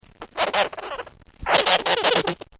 Abt. Wie tönt eigentlich ein Kurzkopfgleitbeutler?